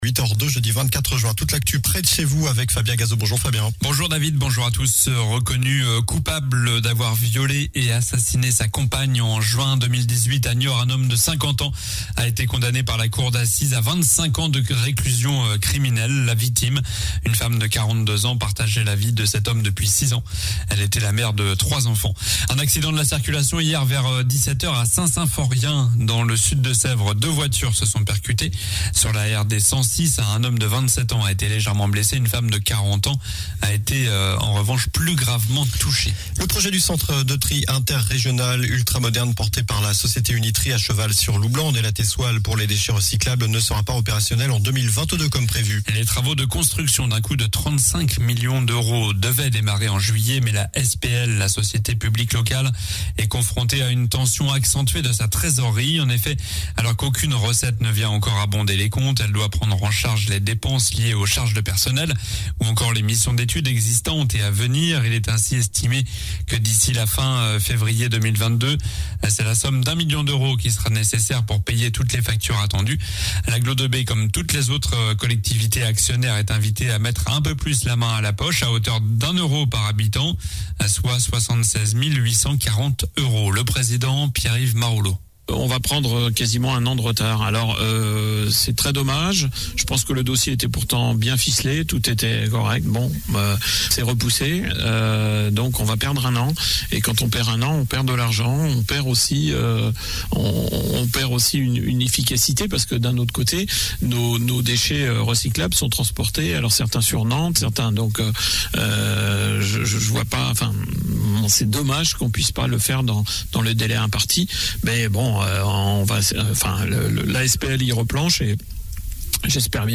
COLLINES LA RADIO : Réécoutez les flash infos et les différentes chroniques de votre radio⬦
Journal du jeudi 24 juin